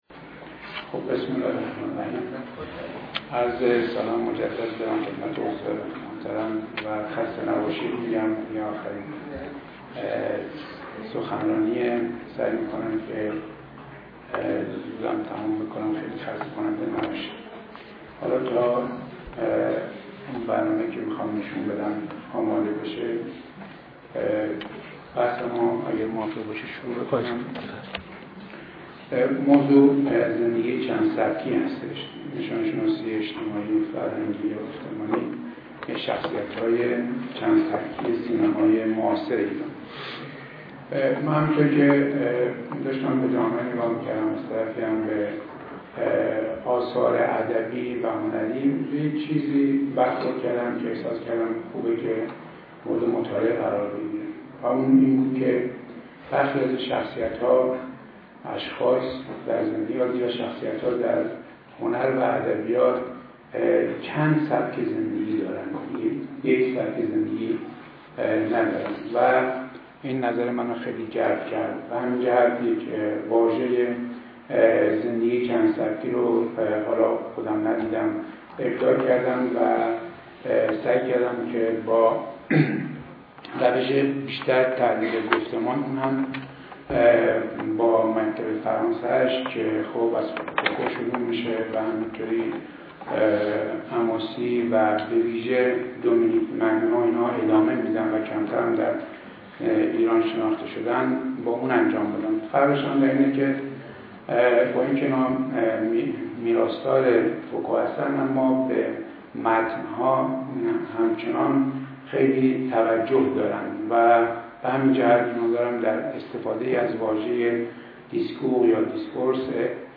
این همایش اسفند ماه ۹۳ در مرکز دایرهالمعارف بزرگ اسلامی برگزار شد